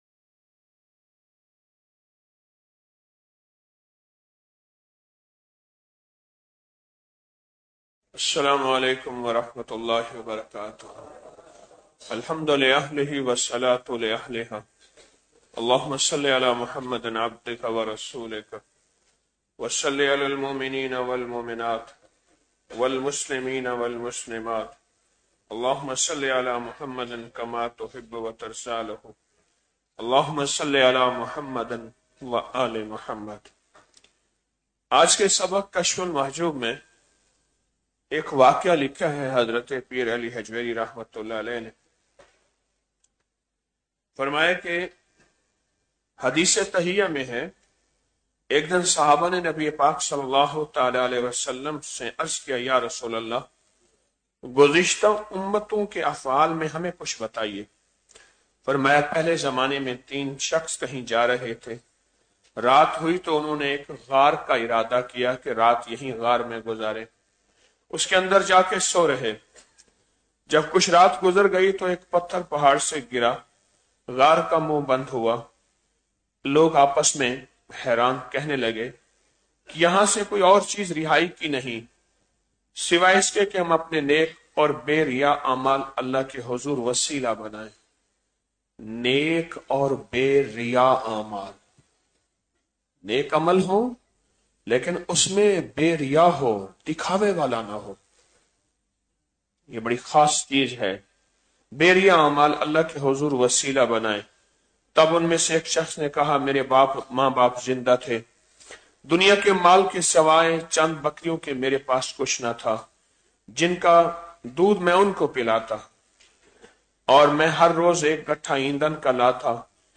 نیکی اور اللہ کی مدد || 16 رمضان المبارک بعد نماز فجر- 06 مارچ 2026ء